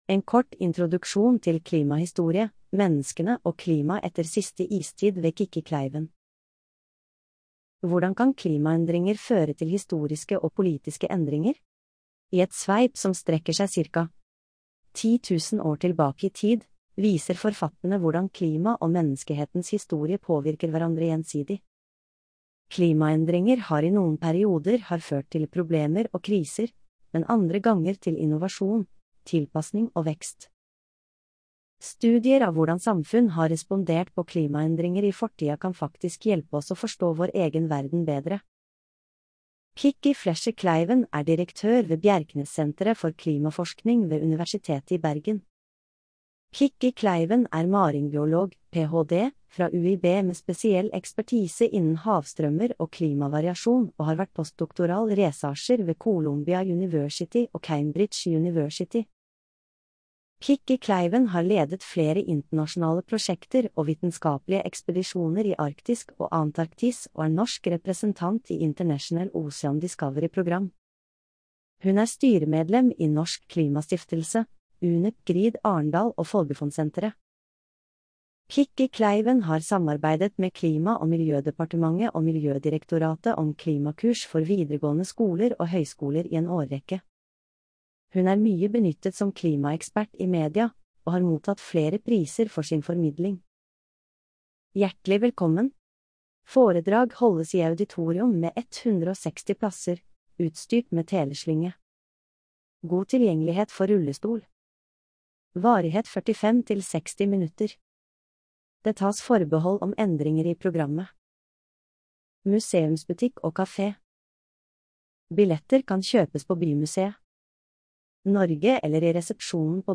Hvordan kan klimaendringer føre til historiske og politiske endringer? Populærvitenskapelige foredrag.